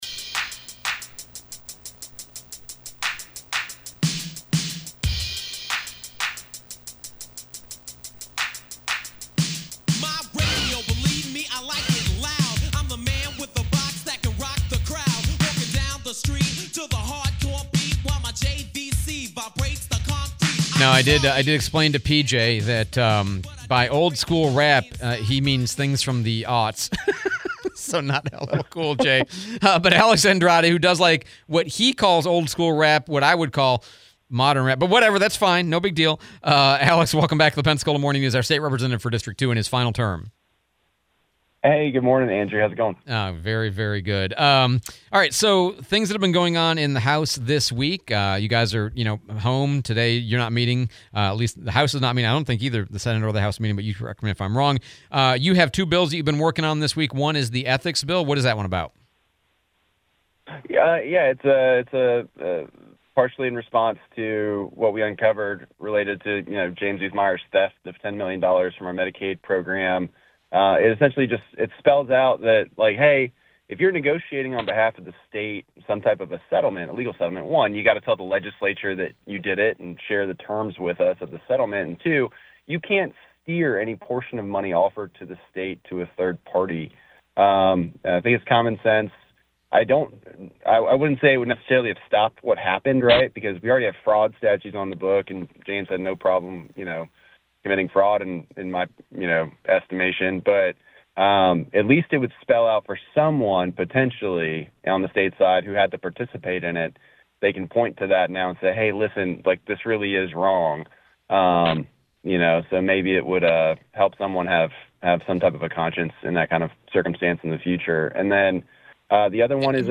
01/30/26 State Rep. Alex Andrade interview